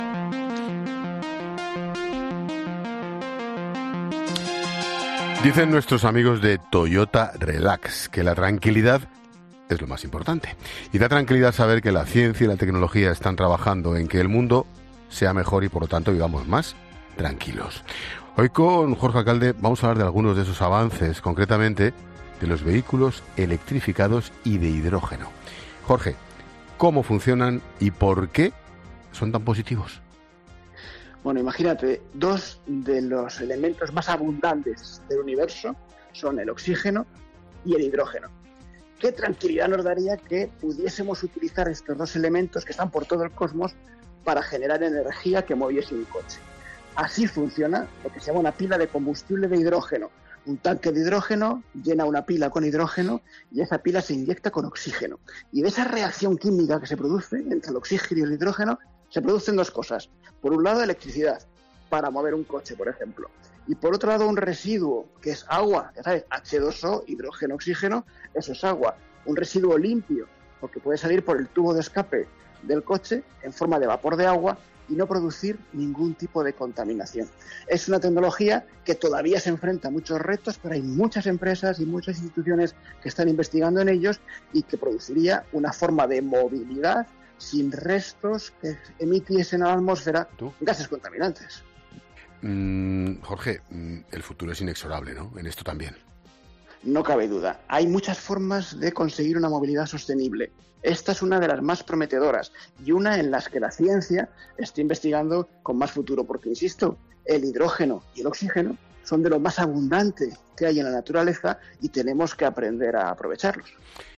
Jorge Alcalde explica a Expósito cómo funcionan las pilas de combustible de hidrógeno y qué depara para el futuro de la movilidad